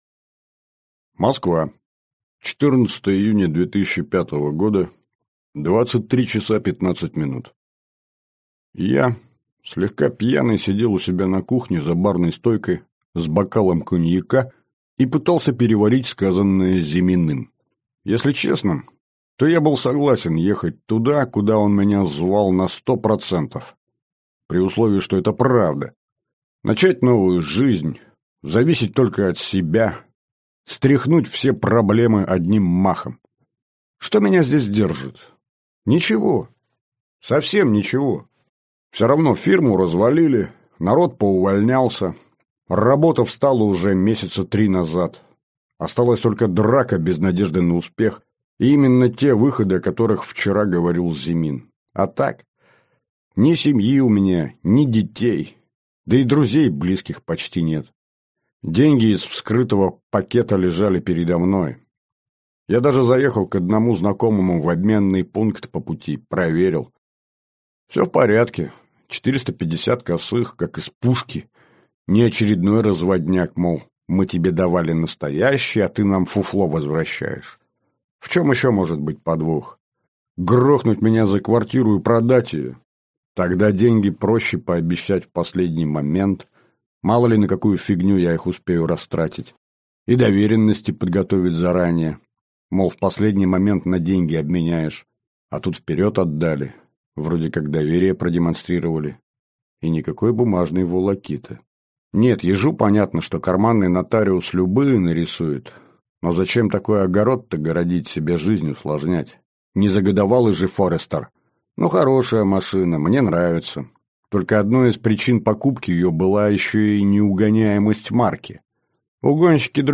Аудиокнига Земля лишних. Трилогия | Библиотека аудиокниг